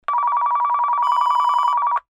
Telephone ring sound effect .wav #4
Description: A single telephone ring
Properties: 48.000 kHz 24-bit Stereo
A beep sound is embedded in the audio preview file but it is not present in the high resolution downloadable wav file.
telephone-ring-preview-4.mp3